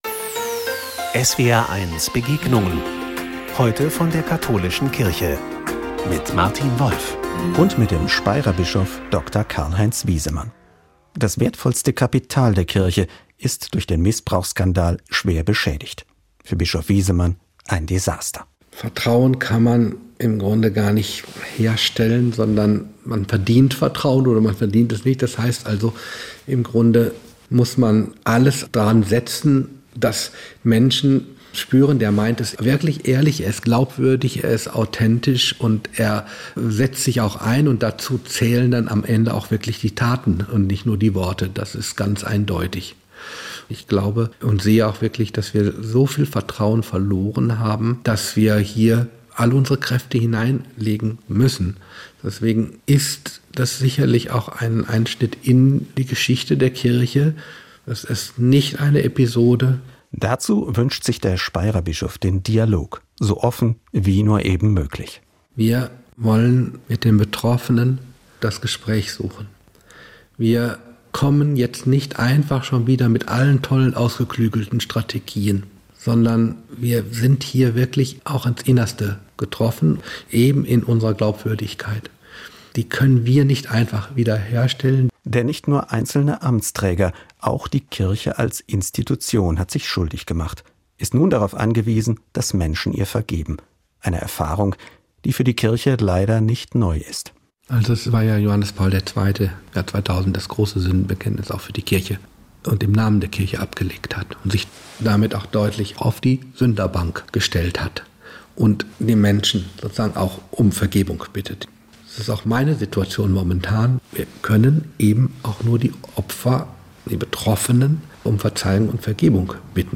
Gespräch Teil 2